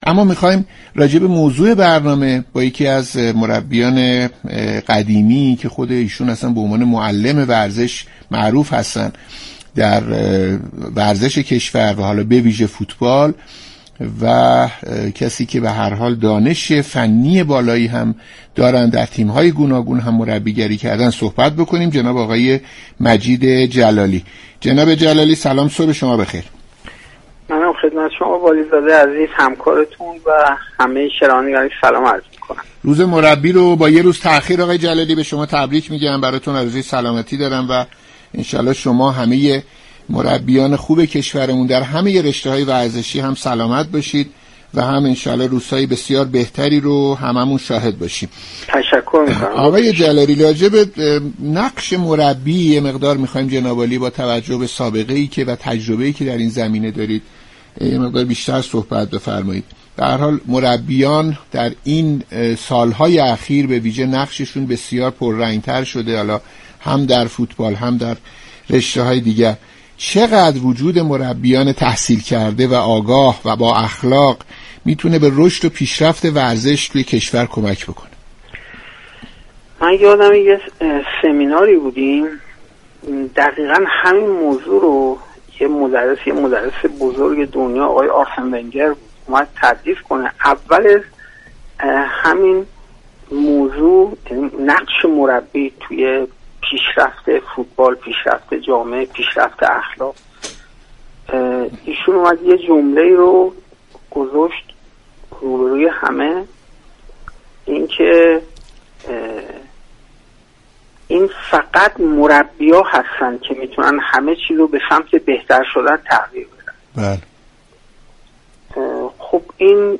برنامه «صبح و ورزش» شنبه 10 آبان در گفتگو با مجید جلالی فراهانی، مربی فوتبال و معلم نامدار ورزش به موضوع نقش مربیان در شكلگیری شخصیت افراد پرداخت.